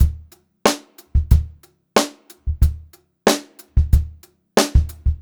92ST2BEAT1-L.wav